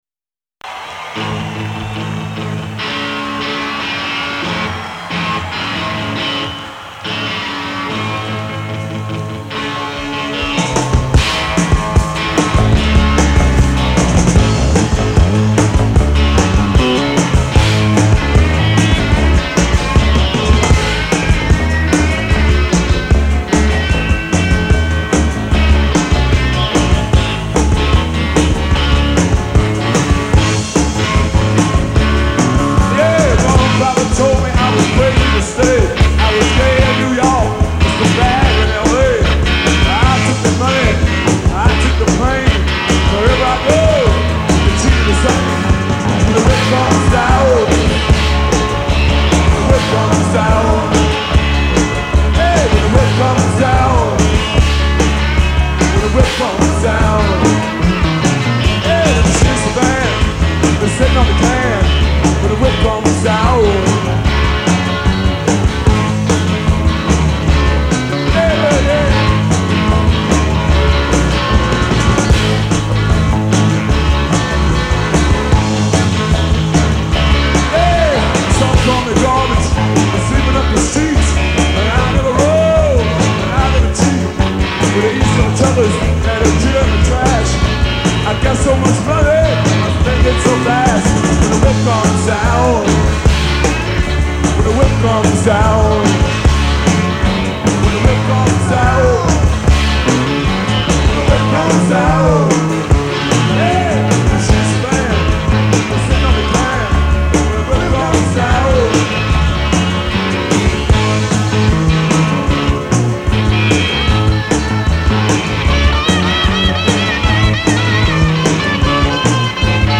This was from the ’81 tour show at Chicago’s Rosemont.